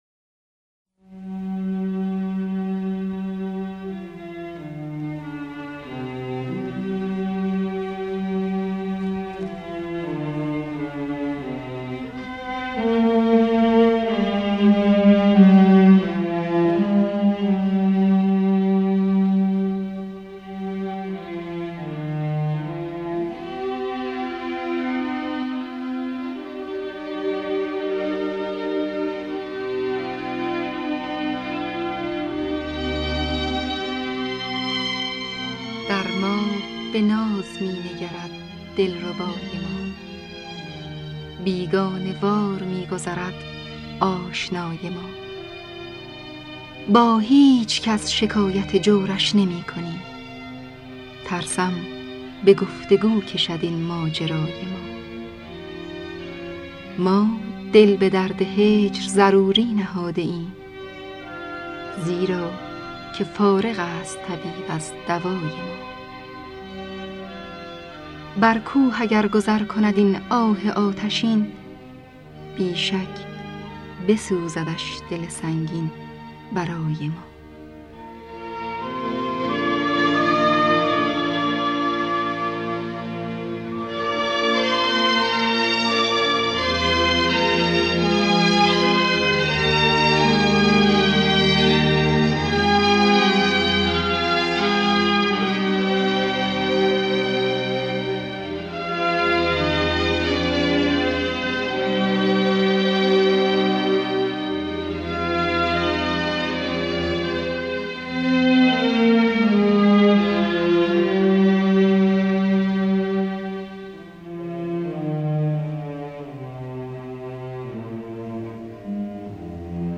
در دستگاه دشتی